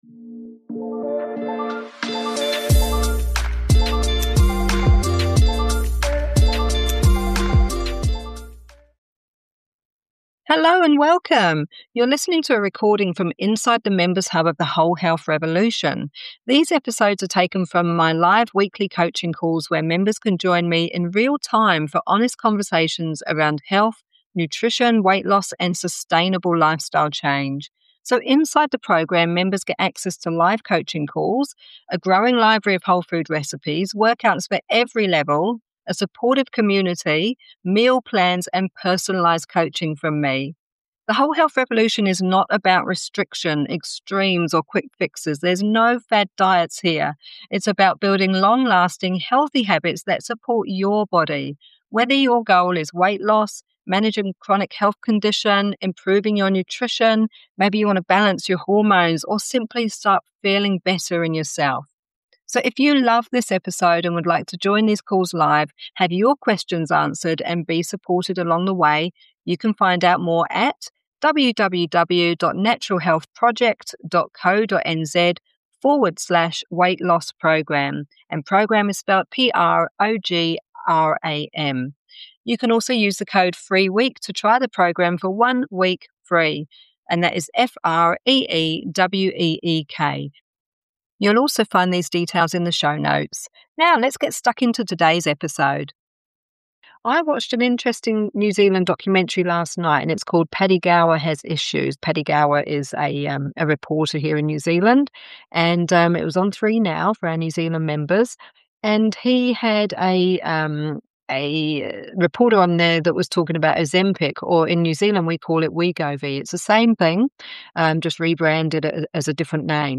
This is an honest, balanced conversation designed to give you the facts.